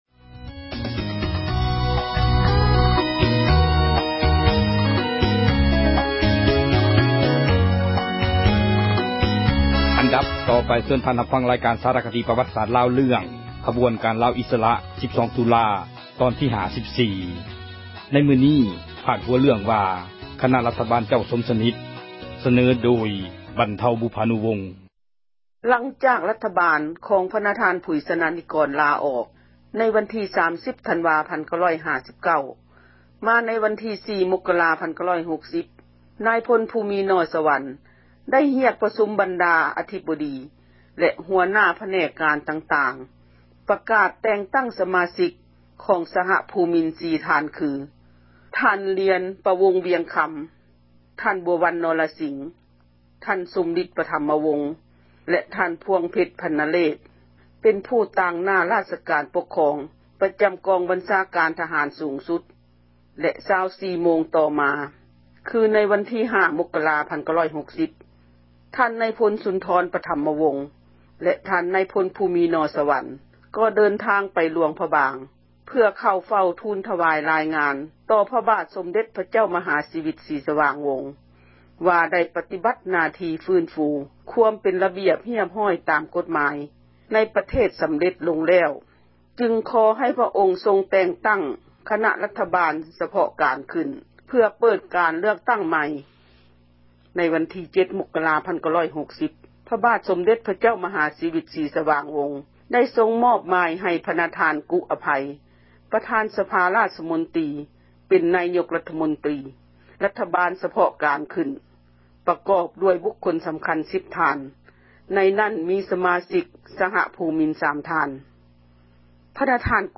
ເຊີນທ່ານຮັບຟັງ ຣາຍການ ສາຣະຄະດີ ປວັດສາດລາວ ປະຈໍາສັປດາ ເຣື້ອງ ”ຂະບວນການລາວອິສຣະ 12 ຕຸລາ” ຕອນທີ 54. ໃນມື້ນີ້ ພາດຫົວເຣື້ອງວ່າ: ຄນະຣັຖບານເຈົ້າ ສົມສະນິດ. ສເນີທ່ານໂດຍ